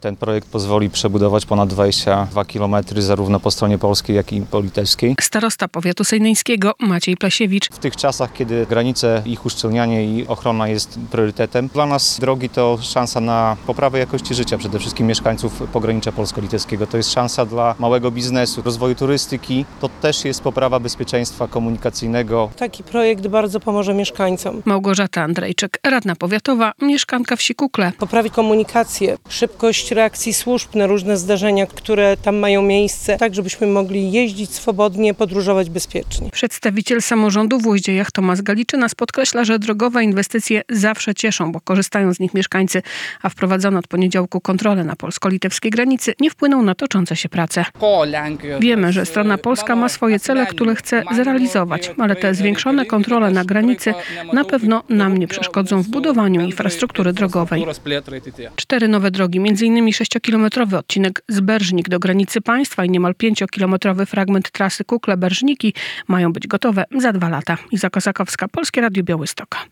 Samorządowcy z Polski i Litwy mówią o konieczności powstania nowych dróg łączących oba kraje - relacja